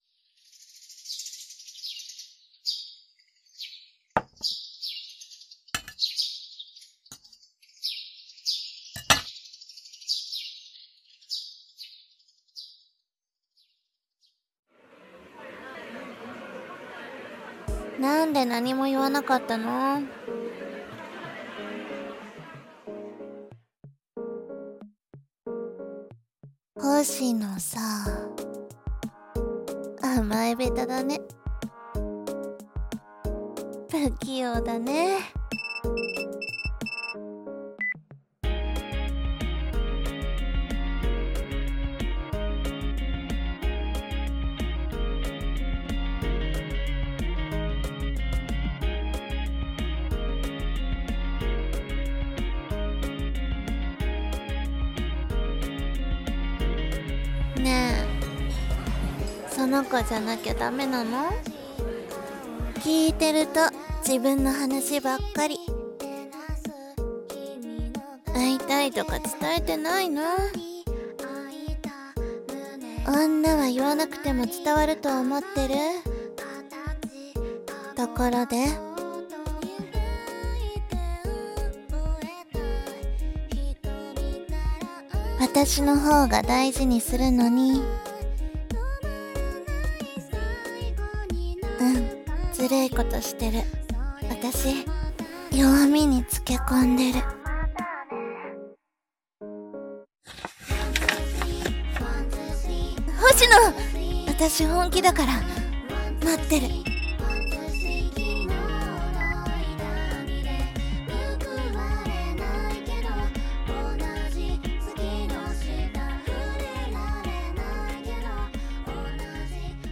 error 【3人声劇】